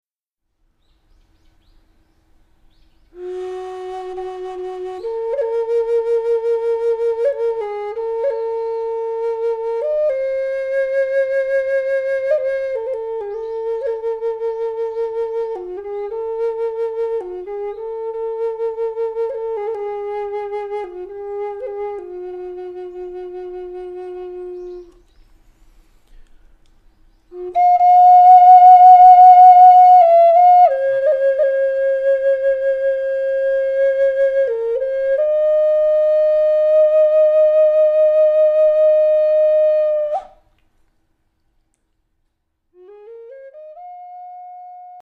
インディアンフル－ト＆ピアノ＆トゥバ民謡・喉歌（フ－メイ）
そっと耳をすますとささやきまでも聴こえてくる無垢で美しいサウンドは、
2007年　秋　即興の記録
～冬支度を始めた「東京・調布」の森にて～
Indian Flute / Bamboo Flute / Indian Drum / Voice
Piano / Pianika
Vocal / Throat-singing / Doshpuluur / Igil / Khomus
Recorded at Mori no Terrace（Tokyo）